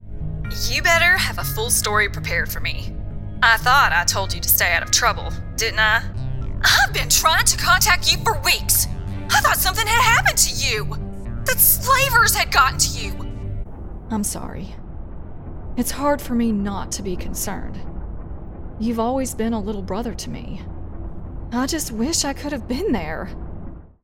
Accents
5028: Sonata of Fate - Maurel Illani (Southern)